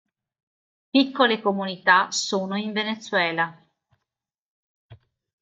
co‧mu‧ni‧tà
Pronounced as (IPA)
/ko.mu.niˈta/